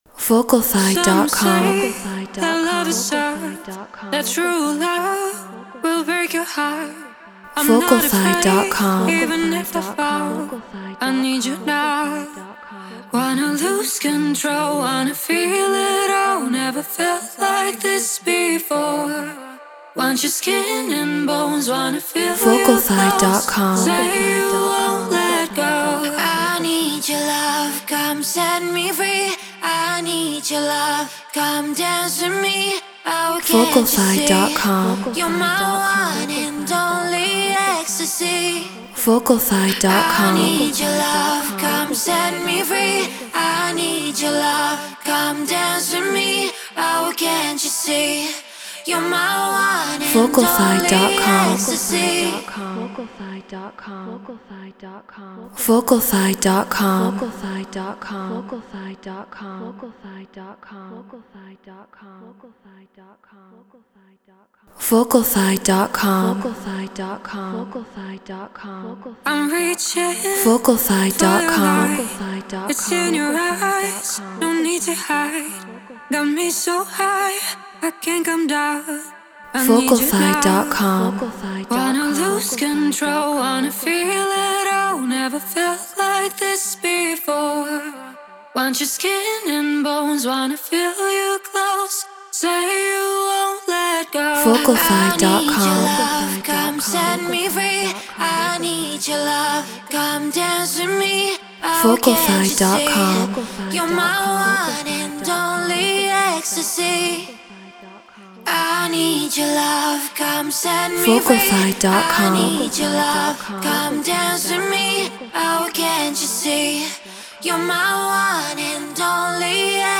EDM 140 BPM Dmin
Treated Room